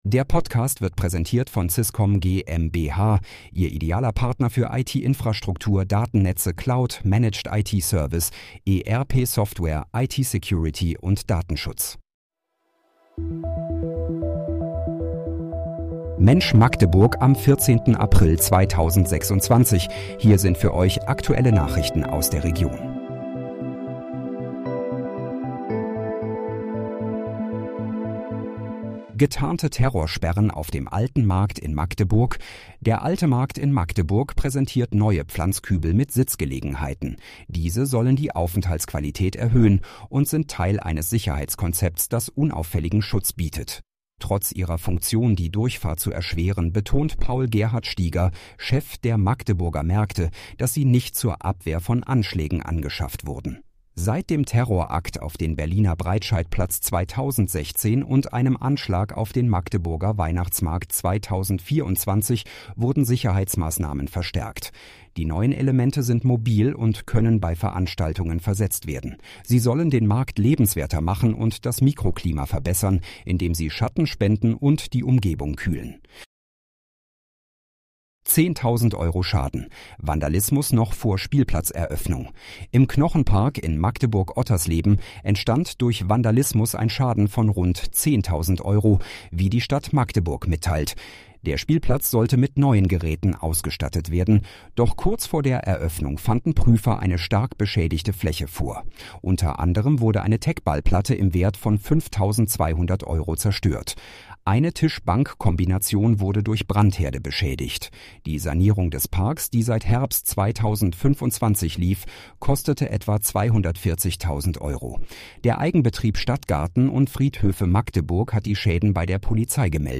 Mensch, Magdeburg: Aktuelle Nachrichten vom 14.04.2026, erstellt mit KI-Unterstützung